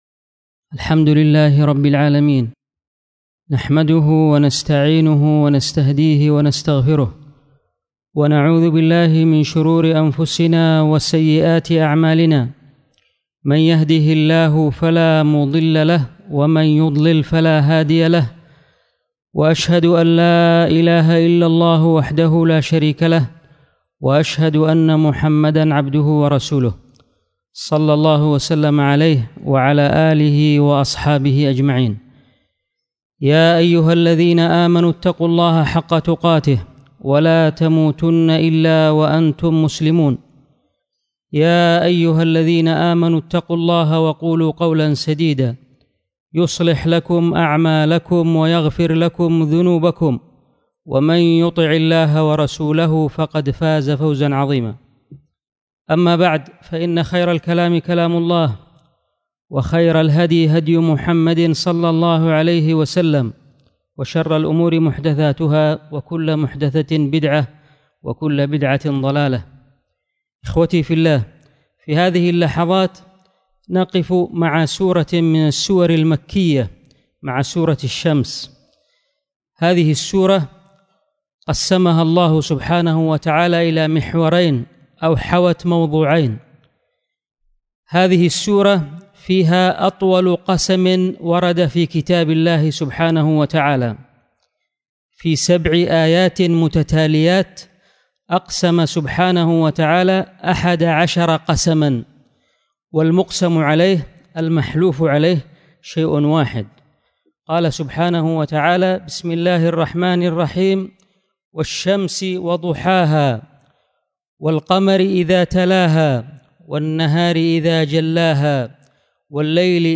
الخطبه